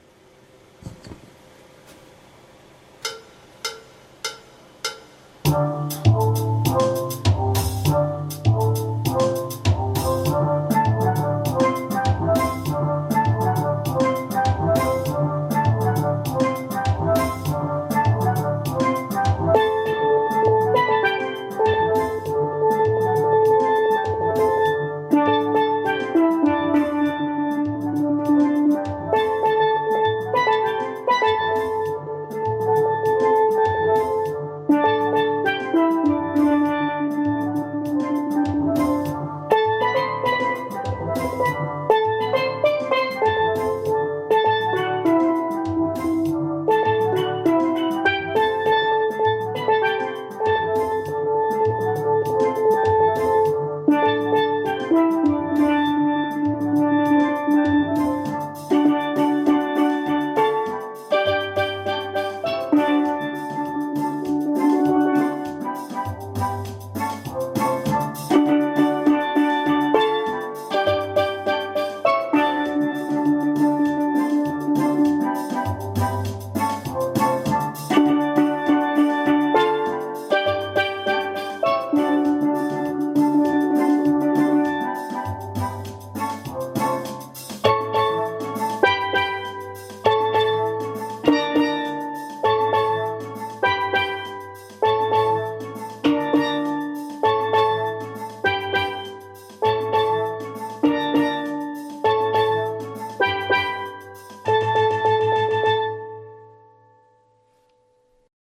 TENOR CALL OF THE WATER DANCER FILM .mp3